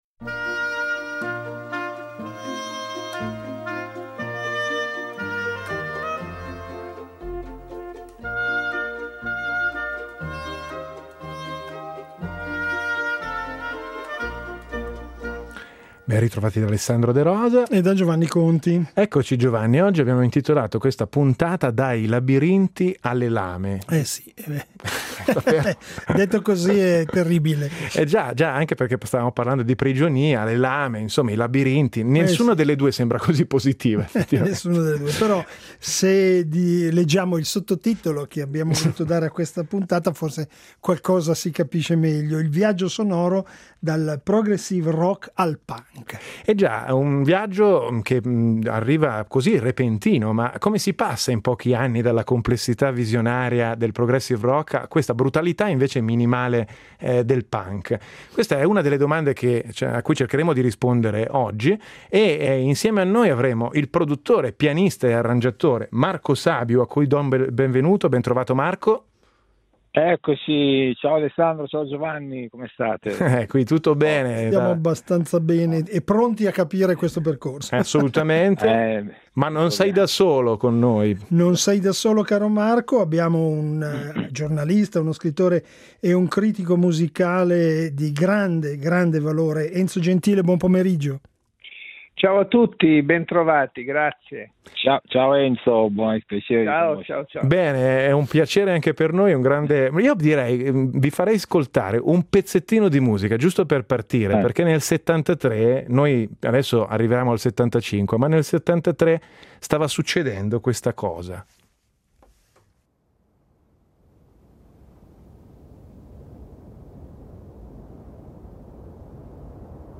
Attraverso ascolti guidati, confronti storici, testimonianze e analisi, Dai Labirinti alle Lame racconta la fine di un’utopia musicale e la nascita di un nuovo linguaggio.
Una discussione per capire come cambia il mondo, e perché la musica è spesso il primo segnale.